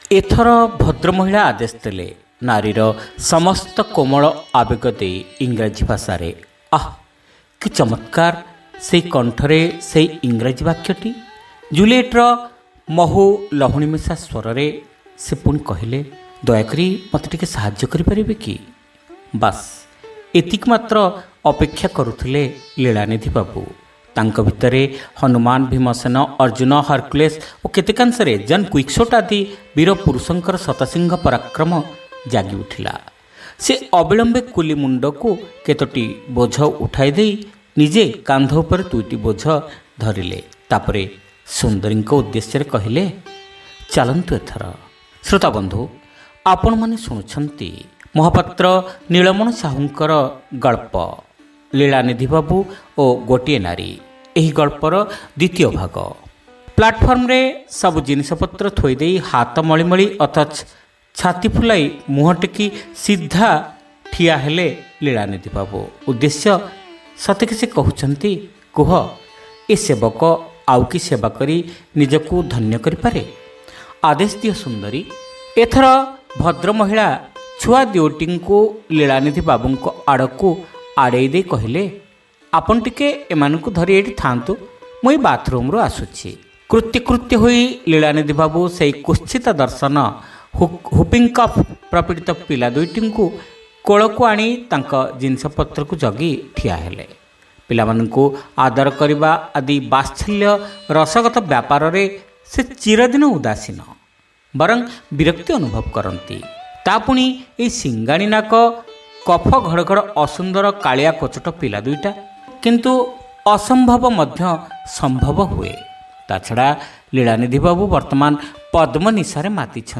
ଶ୍ରାବ୍ୟ ଗଳ୍ପ : ଲୀଳାନିଧି ବାବୁ ଓ ଗୋଟିଏ ନାରୀ (ଦ୍ୱିତୀୟ ଭାଗ)